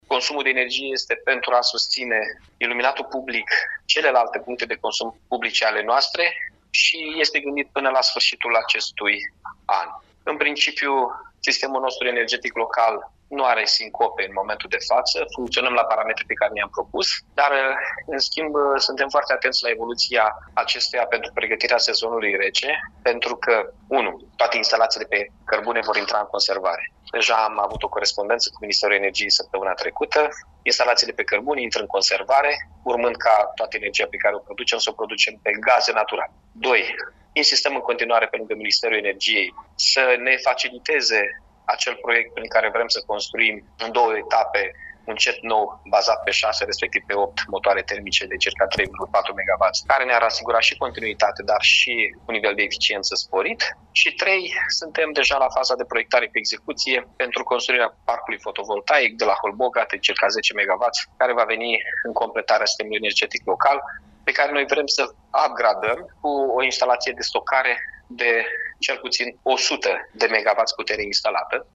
31-mai-ora-11-Chirica-energie.mp3